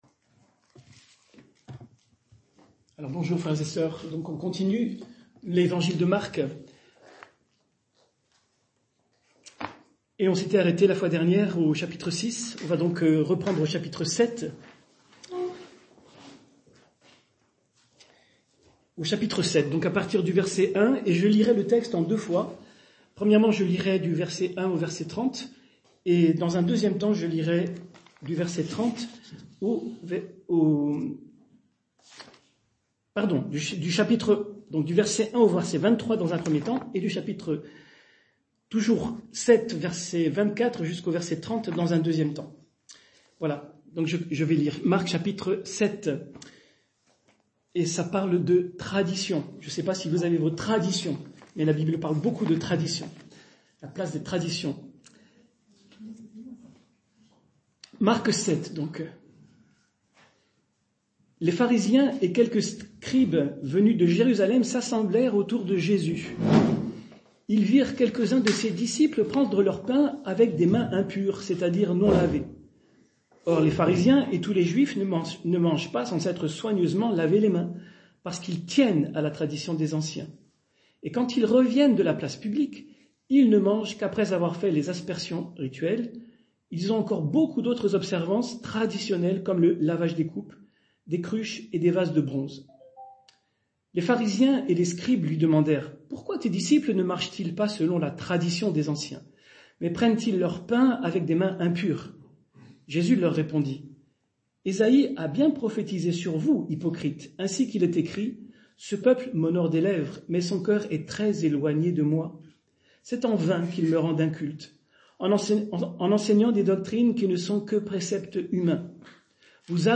Culte du dimanche 2 juin 2024 - EPEF